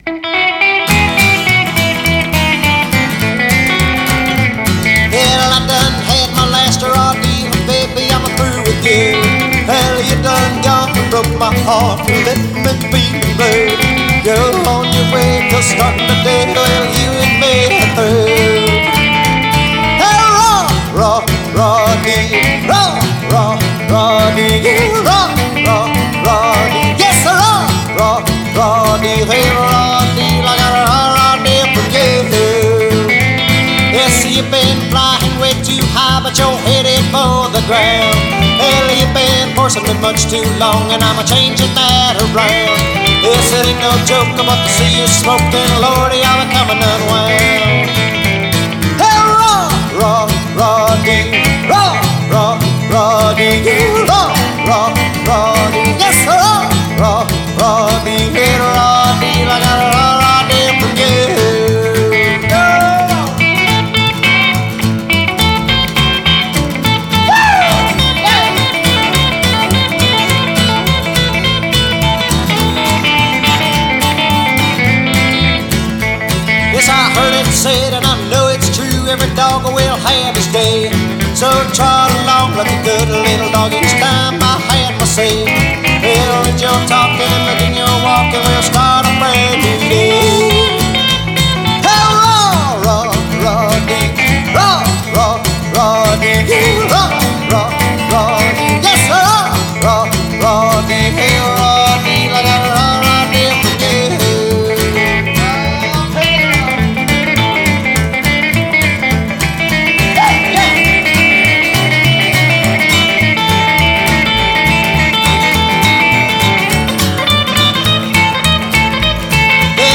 Rockabilly done right!!